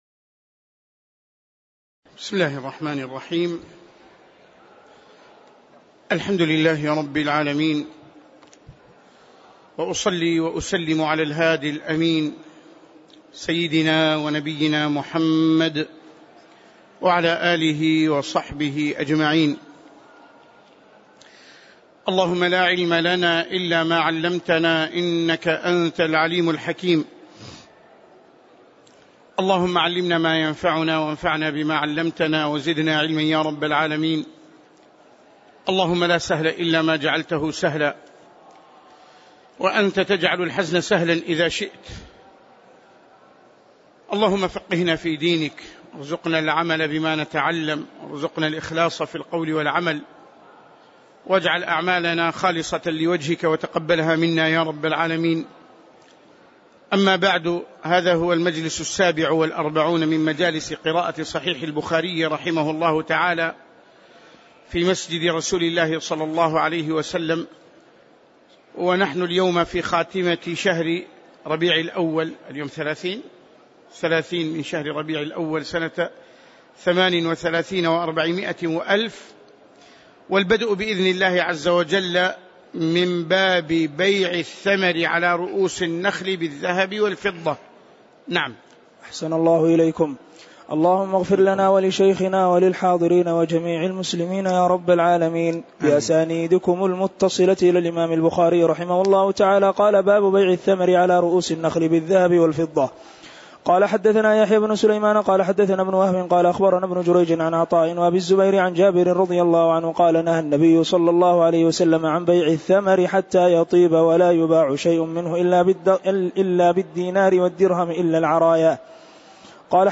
تاريخ النشر ٣٠ ربيع الأول ١٤٣٨ هـ المكان: المسجد النبوي الشيخ